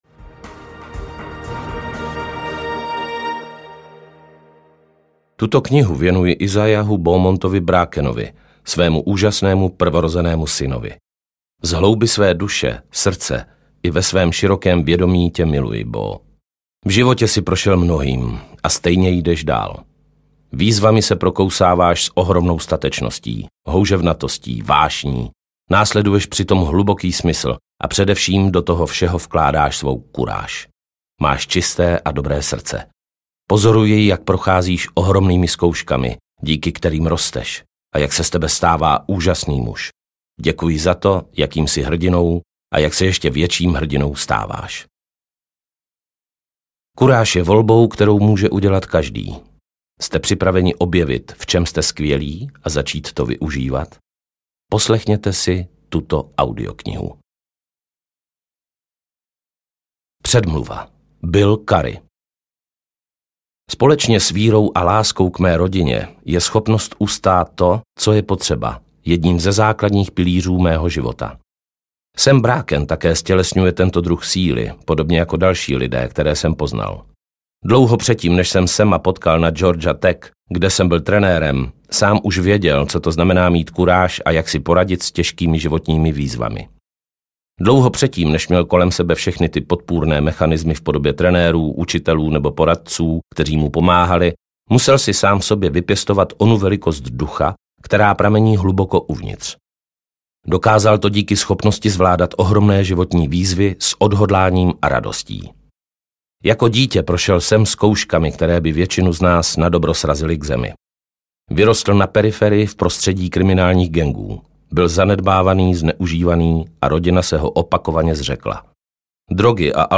Kuráž audiokniha
Ukázka z knihy
• InterpretPavel Nečas